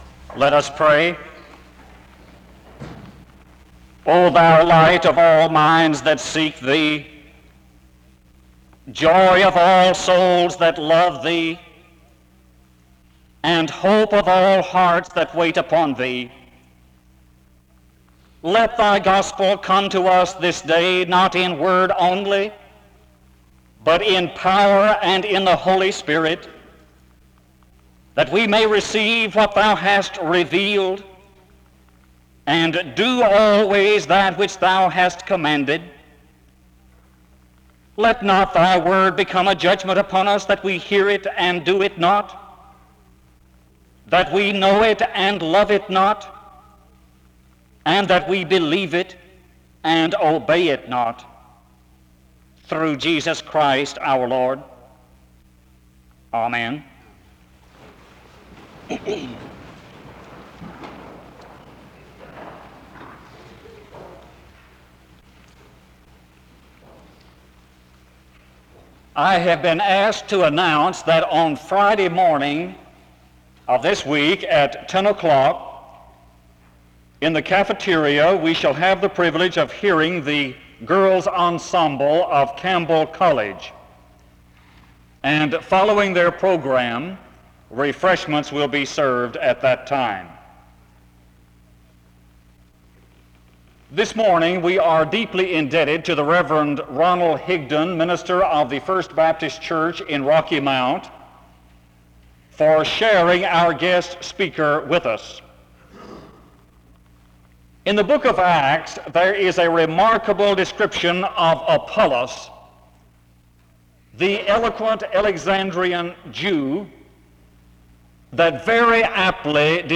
The service begins with a prayer (0:00-1:04).
The service continues with a period of singing (4:03-5:55).
The service concludes in song (31:51-32:26).